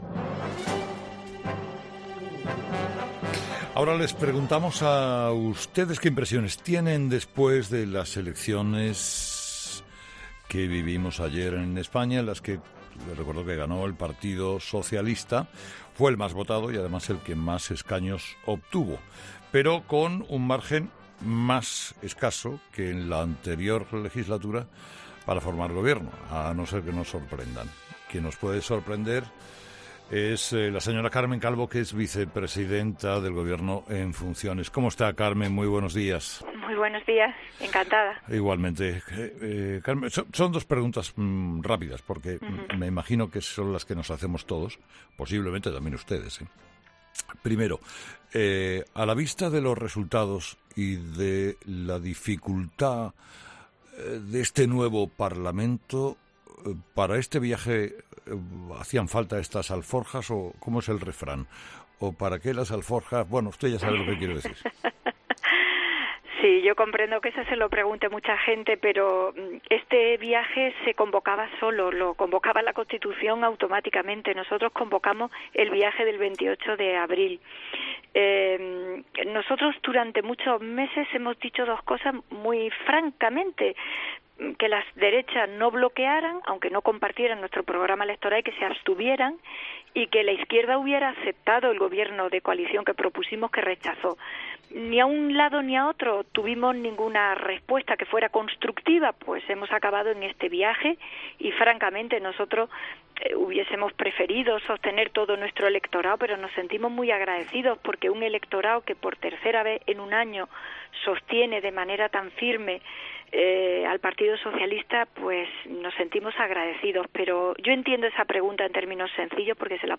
Entrevista a Carmen Calvo COPE
Entrevistado: "Carmen Calvo"
La vicepresidenta del Gobierno en funciones, Carmen Calvo, ha sido entrevistada este lunes en 'Herrera en COPE' tras los comicios del 10-N. Después de perder tres diputados y con una fragmentación todavía mayor del arco parlamentario que hace más difícil la formación de Gobierno, la socialista ha dicho que las elecciones fueron convocadas "por la Constitución automáticamente" tras no lograr el apoyo a Sánchez en primera vuelta en julio.